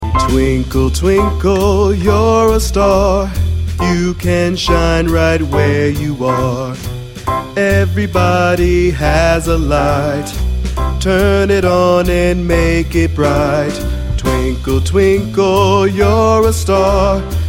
with pizzazz